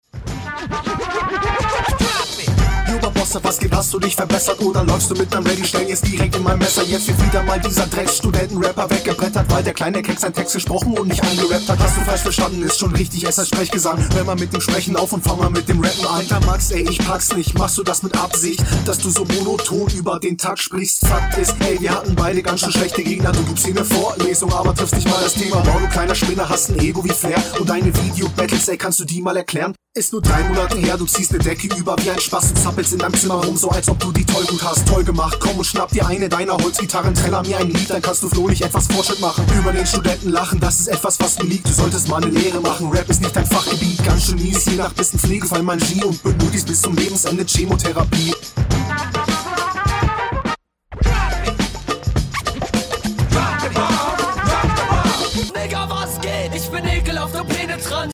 Flow nice, wird besser.
Netter Flow, Gegnerbezug ist da, stabile Runde.